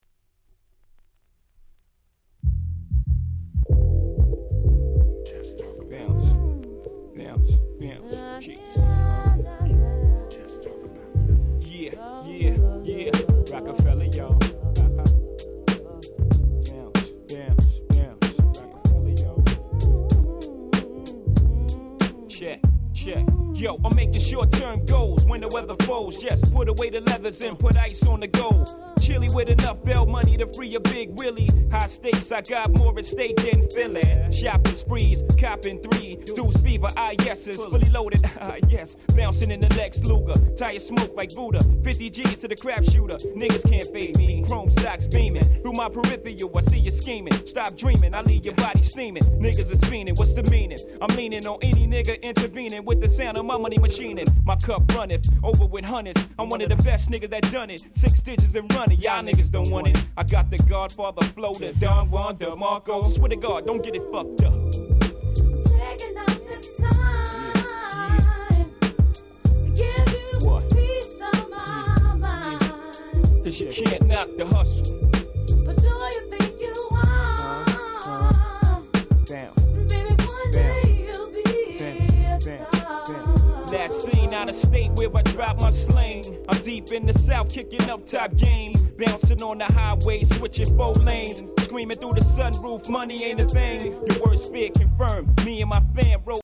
96' Smash Hit Hip Hop !!
Album Version Dirty